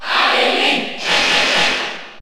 Category: Crowd cheers (SSBU)
Link_&_Toon_Link_Cheer_French_PAL_SSBU.ogg